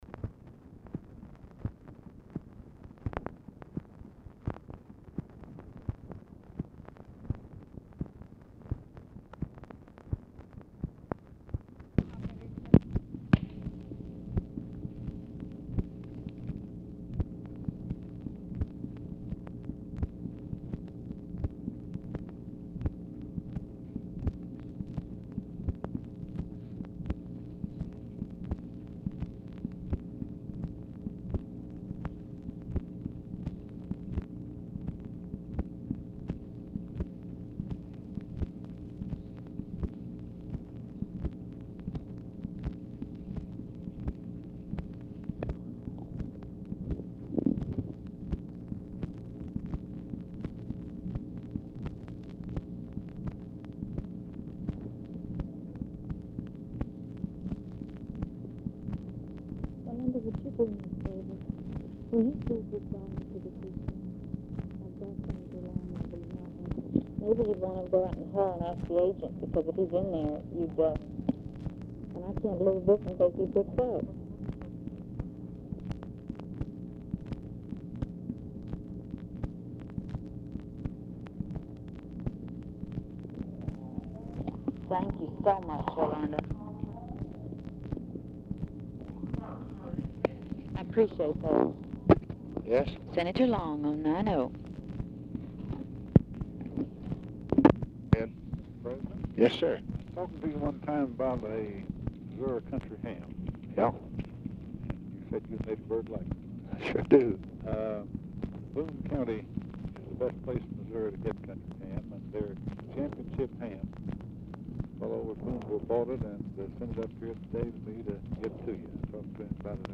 Telephone conversation # 8809, sound recording, LBJ and EDWARD LONG, 9/1/1965, 1:11PM | Discover LBJ
OFFICE CONVERSATION BETWEEN OFFICE SECRETARIES PRECEDES CALL; LONG ON HOLD 1:32
Format Dictation belt
Location Of Speaker 1 Oval Office or unknown location